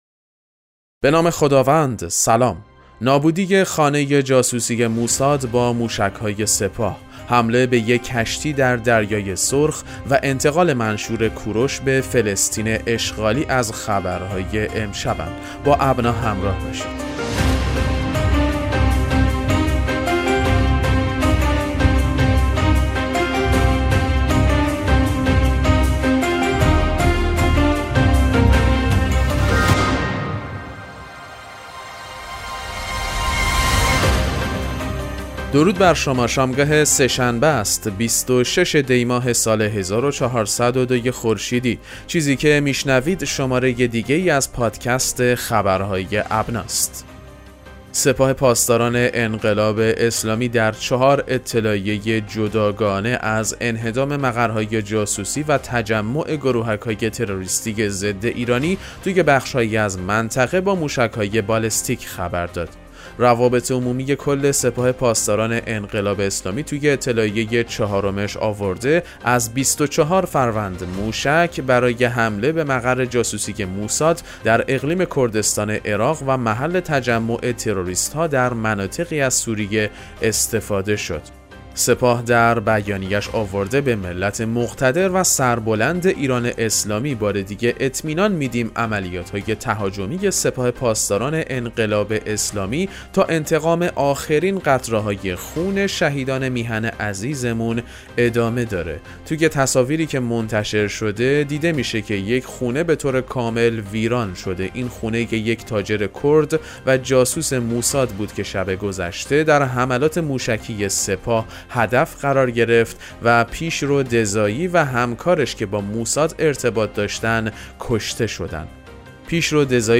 پادکست مهم‌ترین اخبار ابنا فارسی ــ 26 دی 1402